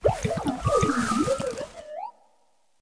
Cri d'Arboliva dans Pokémon Écarlate et Violet.